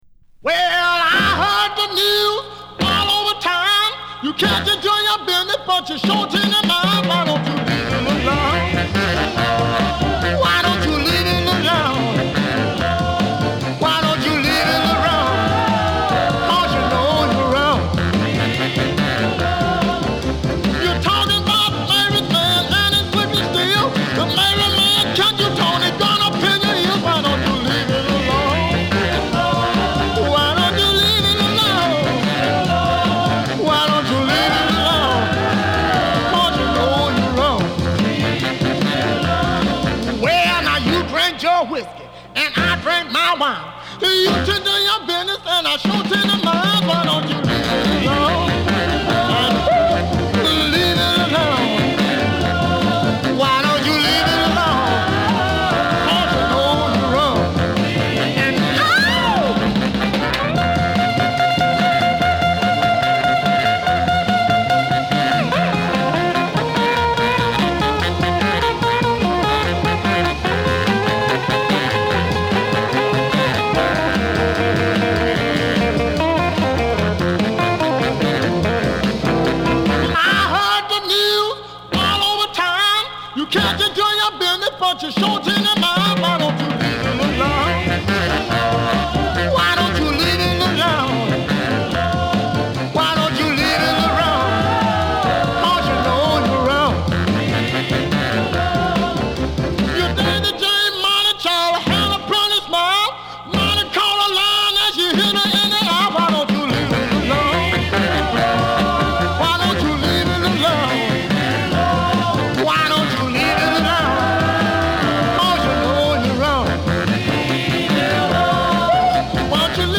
一聴して熱血漢とわかる歌いっぷりに吸い込まれる。
[Jivers/Boppers/Strollers] [Comped] [NEW]